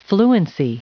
Prononciation du mot fluency en anglais (fichier audio)
Prononciation du mot : fluency